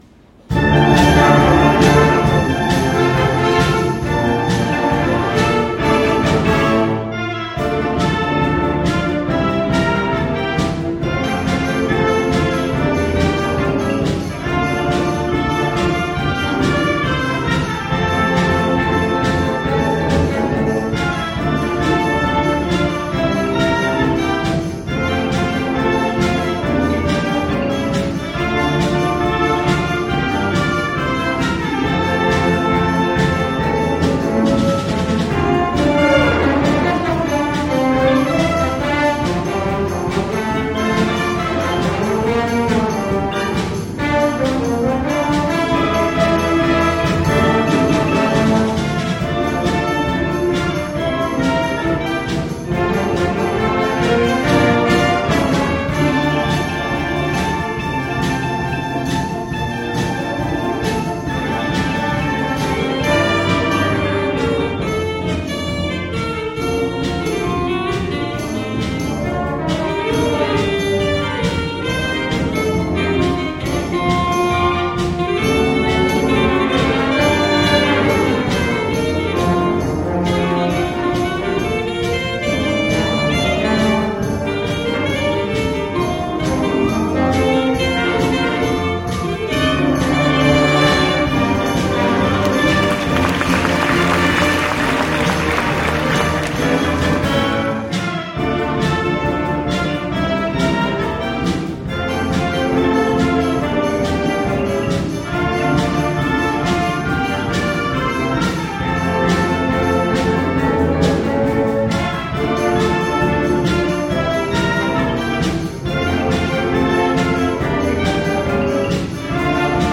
第41回文化発表会の舞台発表は、生徒会による活動報告と吹奏楽部の活気ある演奏で幕を開けました。
The stage performances at the 41st Culture Festival kicked off with a report on activities by the student council and a dynamic performance by the brass band.